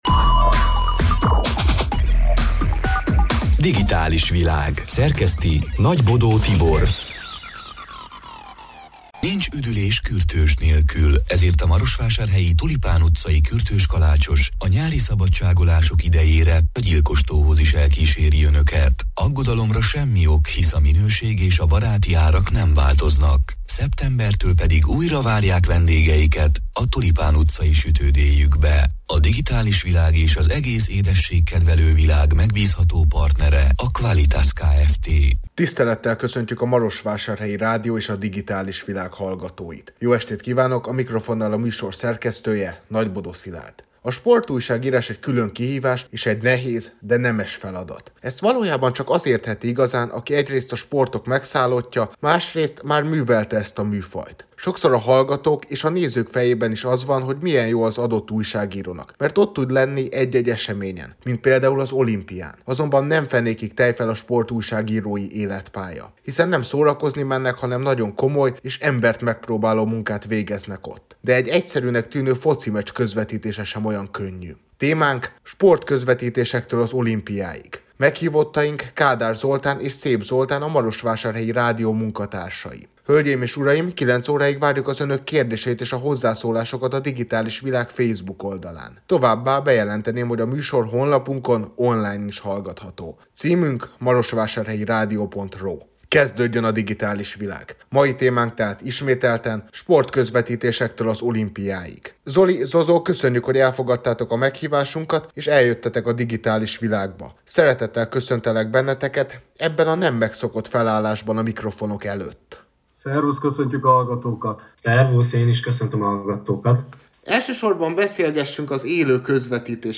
Digitális Világ c. műsorának hanganyaga: